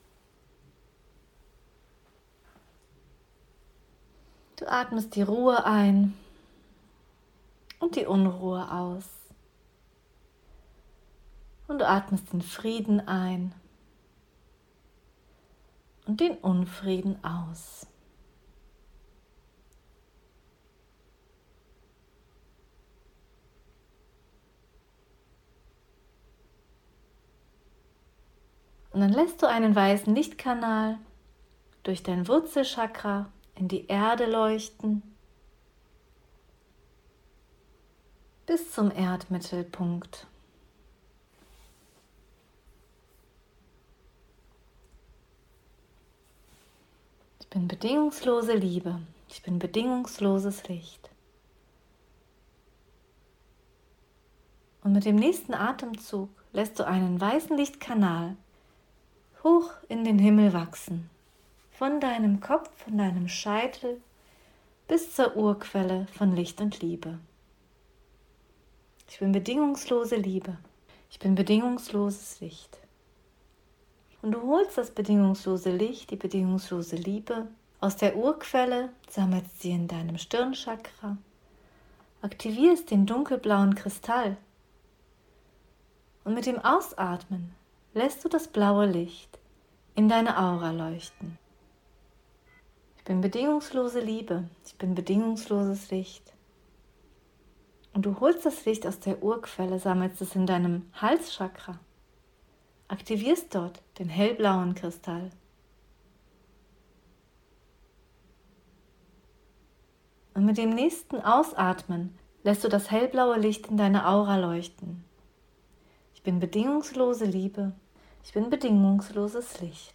Hier ist Deine Meditation: